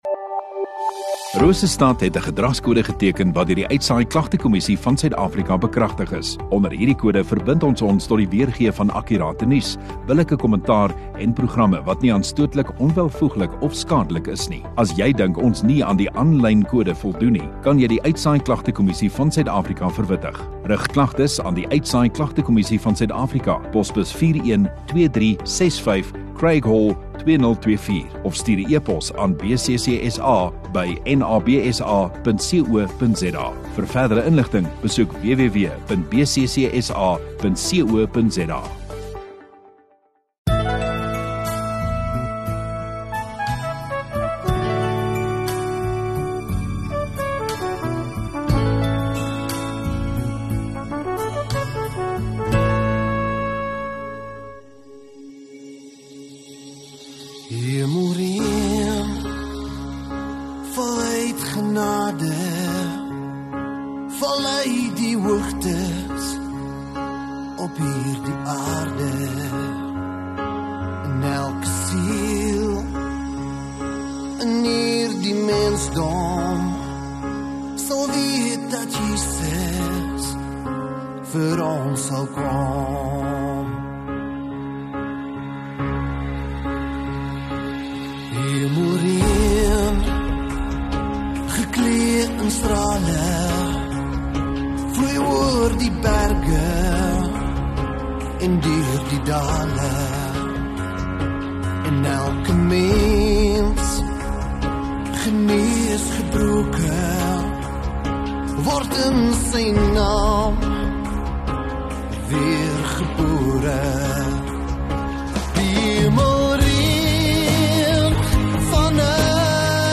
1 Oct Sondagoggend Eredins